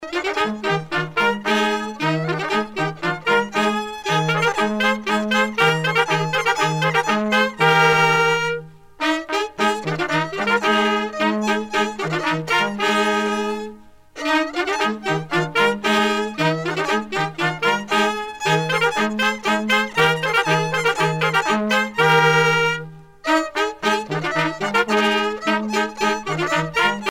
Usage d'après l'analyste gestuel : danse ;
Edition discographique groupe folklorique Fontevraud l'abbaye
Pièce musicale éditée